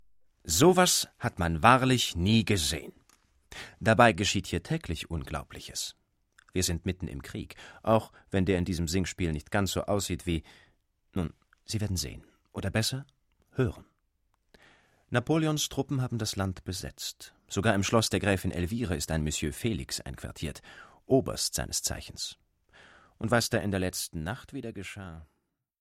Die vorliegende Einspielung beruht auf der Uraufführung des gesamten Werks bei der Theater&Philharmonie Thüringen und wurde im September 2008 im Konzertsaal der Bühnen der Stadt Gera aufgenommen.
Ouvertüre